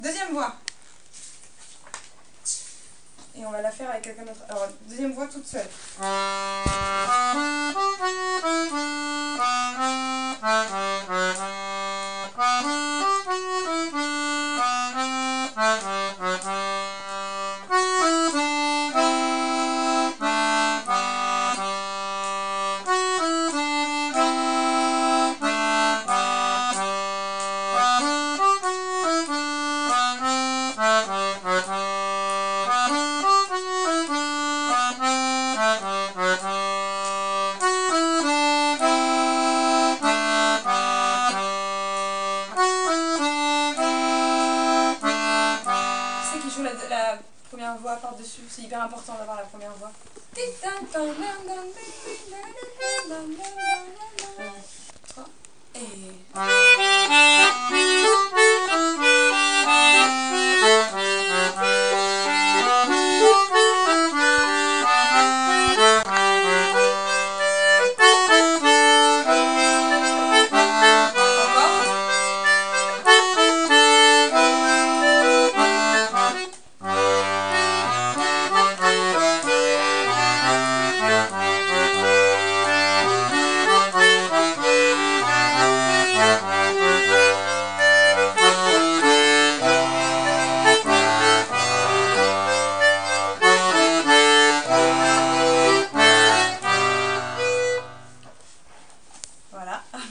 l'atelier d'accordéon diatonique
a mama 2ème voix,
a mama 2 voix.mp3